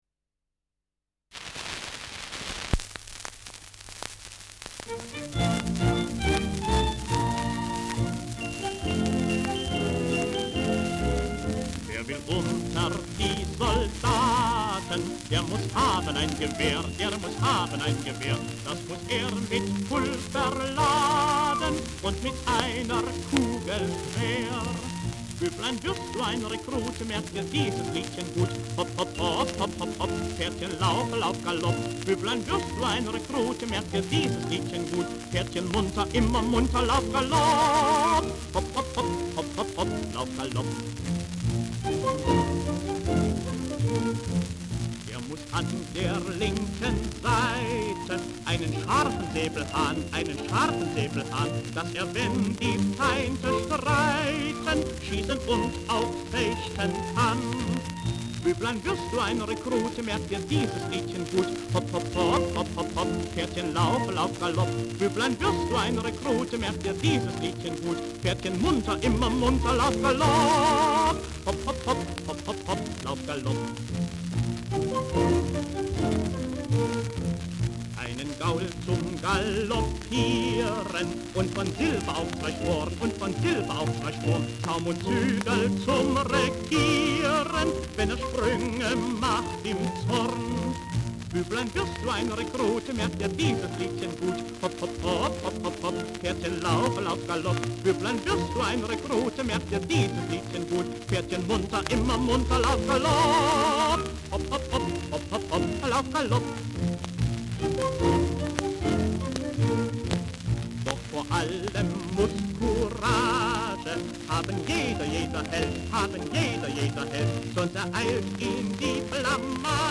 Schellackplatte
Stärkeres Grundrauschen : Gelegentlich leichtes bis stärkeres Knacken
[unbekanntes Ensemble] (Interpretation)